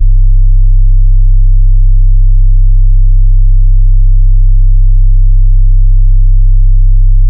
DDW Bass 2.wav